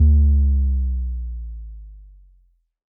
Bass Power Off 6.wav